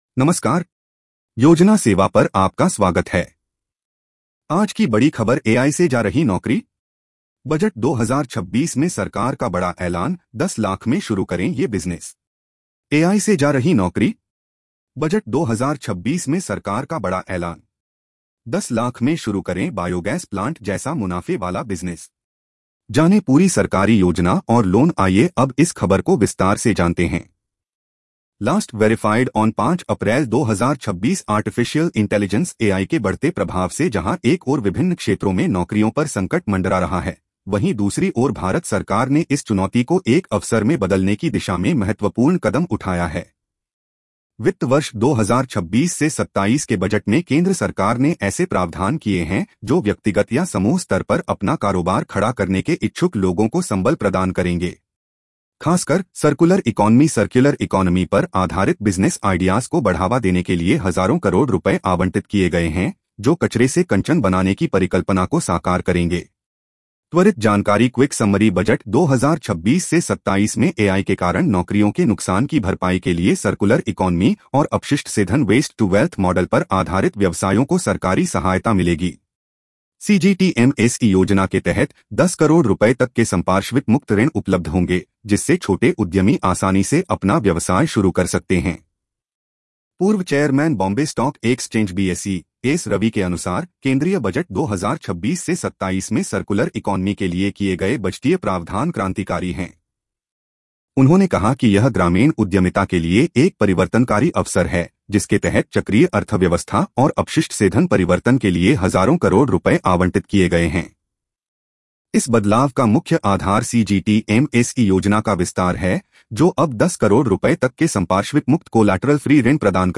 🎧 इस खबर को सुनें (AI Audio):